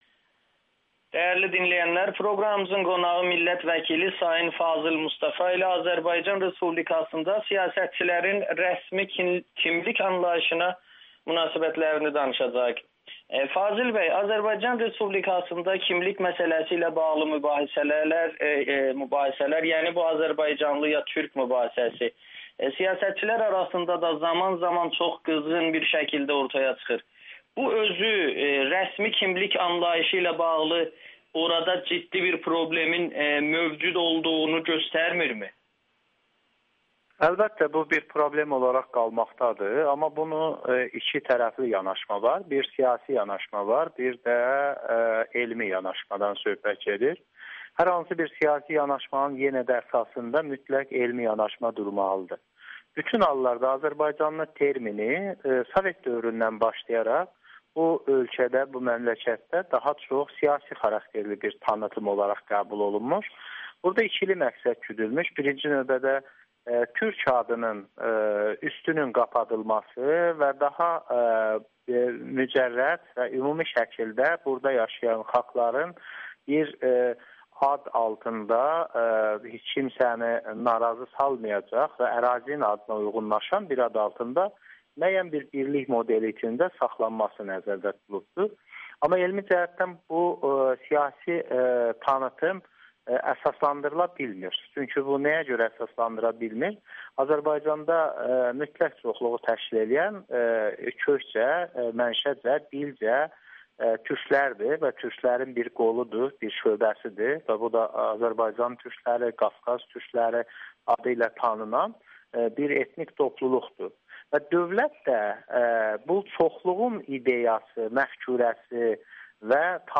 Fazil Mustafa Amerikanın Səsinə danışır